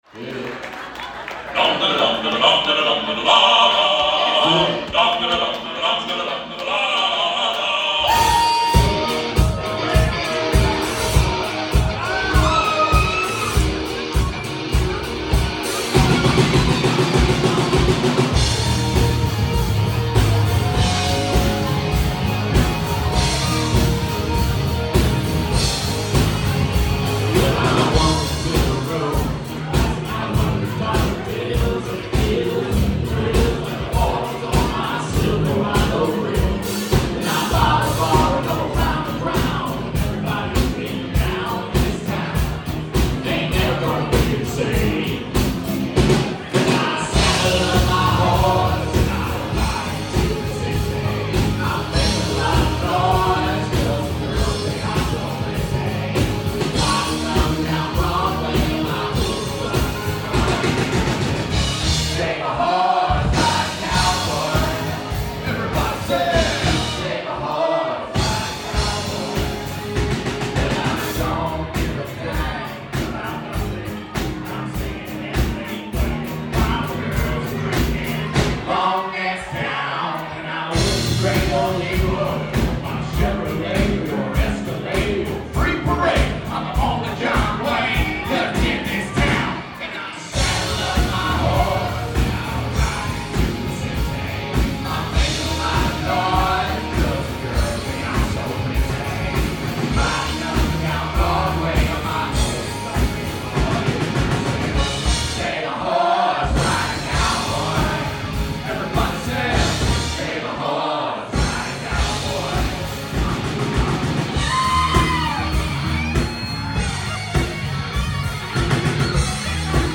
studio and live recordings!